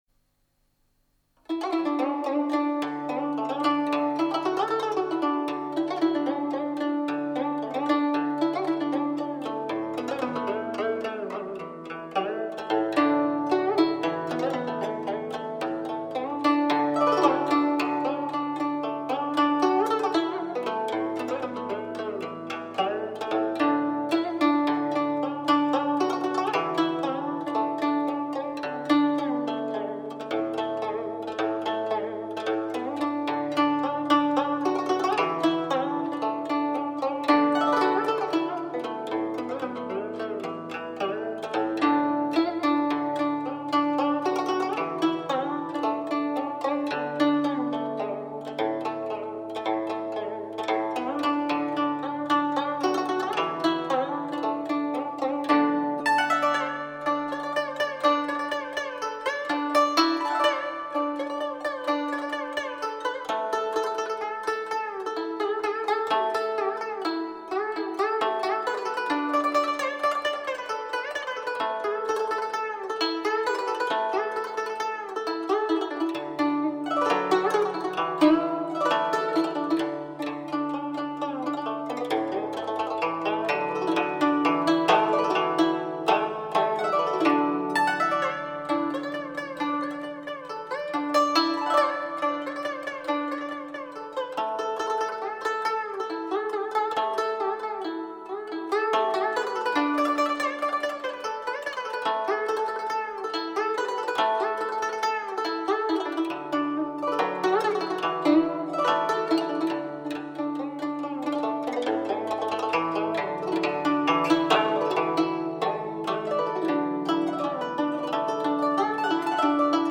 优美的古筝..